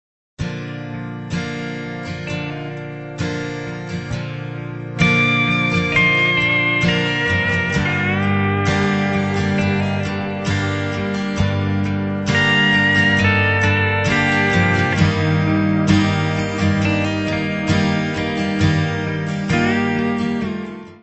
voz, guitarras acústicas
baixo, coros
bateria, pandeireta, shaker
guitarras eléctricas
hammond, piano, pandeireta.
trompete
trombone
: stereo; 12 cm
Área:  Pop / Rock